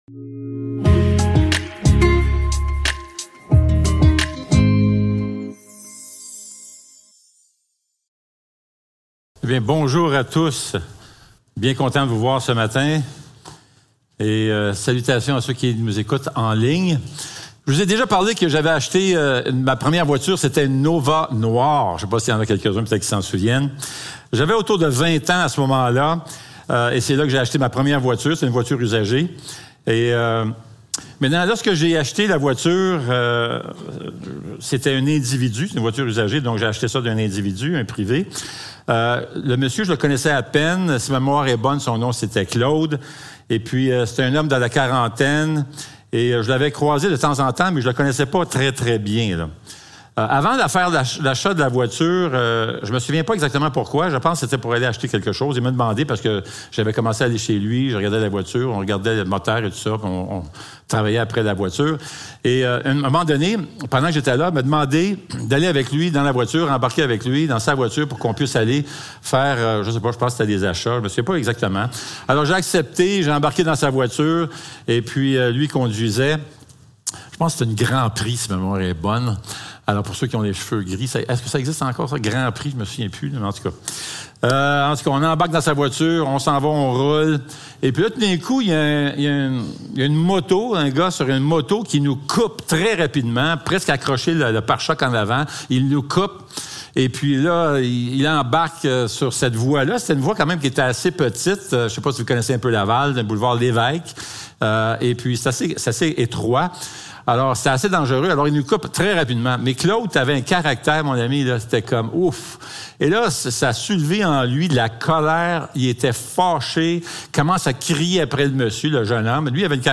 Psaumes 103.1-14 Service Type: Célébration dimanche matin Description